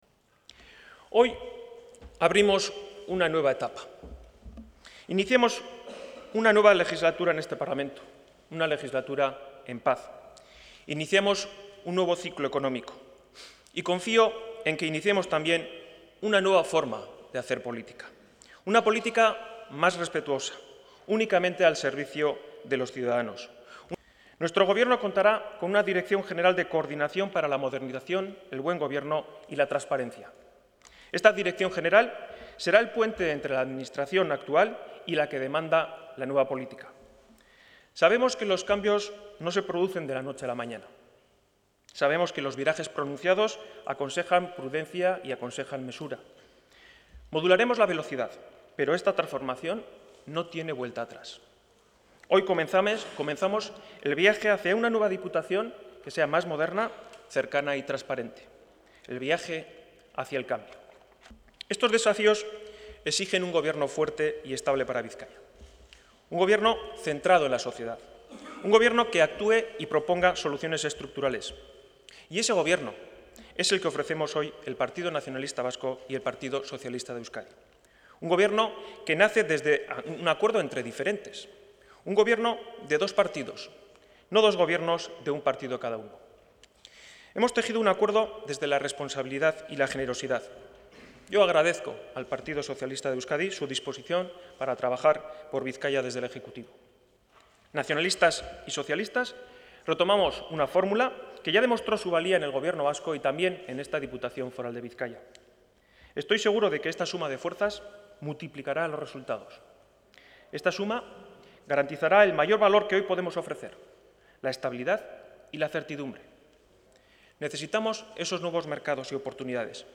• Sesión investidura diputado general de Bizkaia 01/07/2015